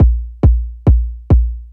Index of /90_sSampleCDs/Best Service ProSamples vol.45 - Techno ID [AIFF, EXS24, HALion, WAV] 1CD/PS-45 AIFF Techno ID/PS-45 AIF loops/AIF drum-loops/AIF main-version